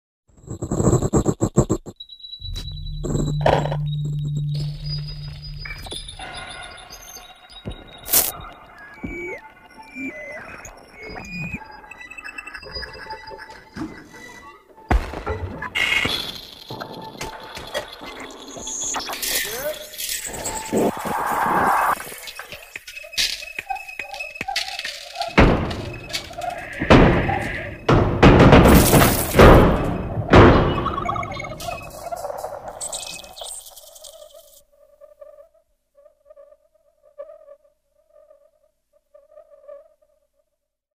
serial tape compositions (1979-82)